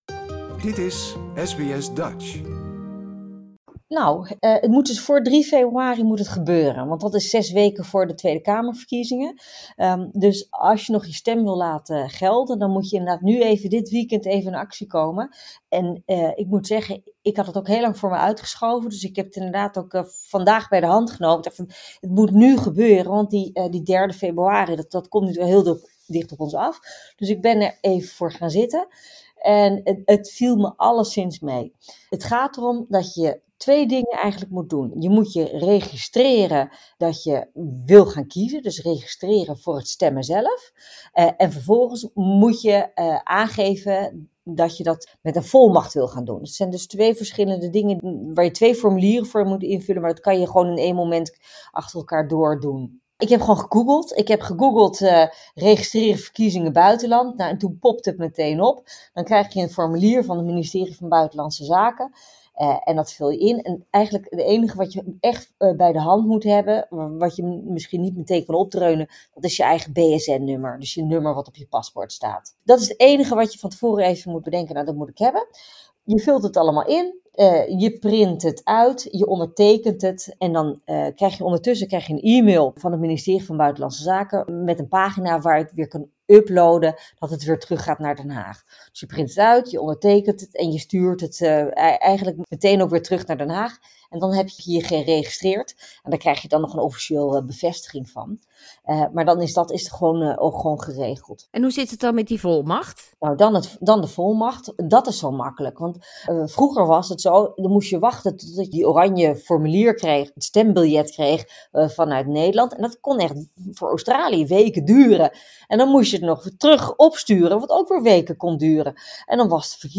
Tot aan 3 februari kun je le laten registreren om te stemmen bij de Nederlandse Parlementsverkiezingen en politiek commentator Nicolien van Vroonhoven legt even uit hoe makkelijk dat is.